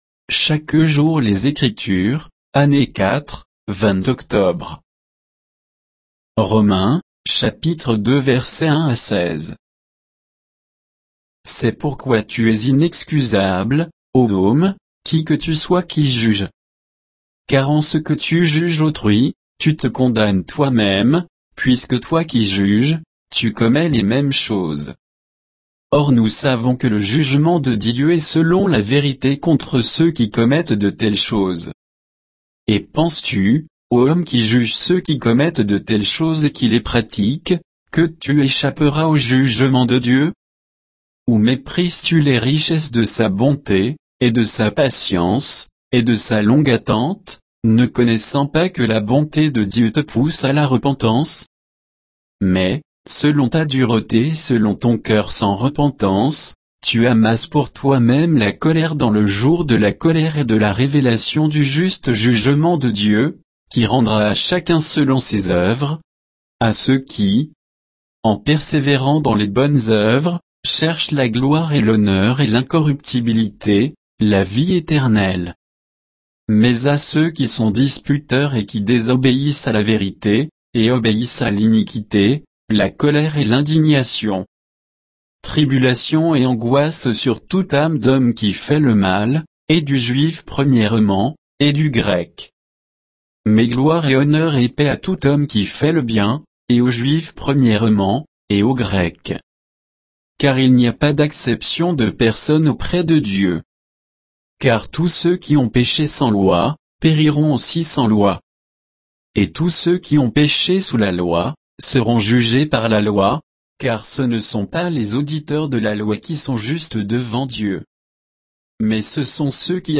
Méditation quoditienne de Chaque jour les Écritures sur Romains 2, 1 à 16